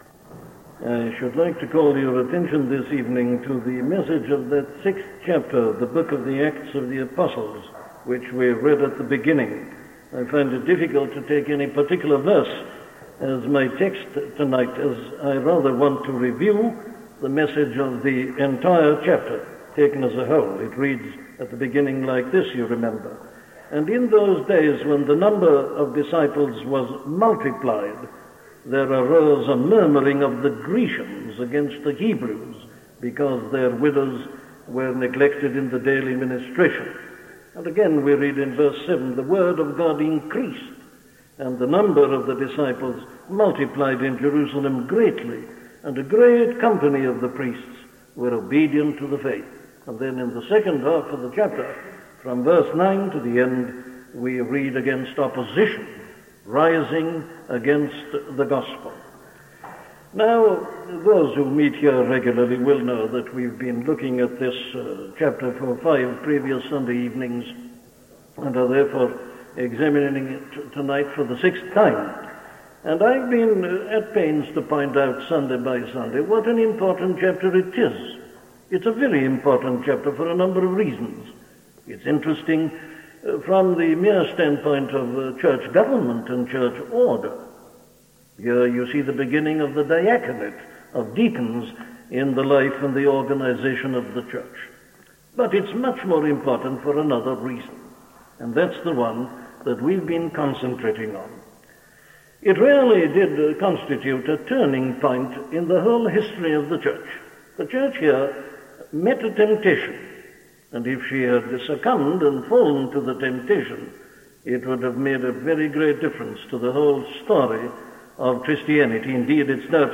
The Church and Her Message - a sermon from Dr. Martyn Lloyd Jones
Listen to the sermon on Acts 6:1-7 'The Church and Her Message' by Dr. Martyn Lloyd-Jones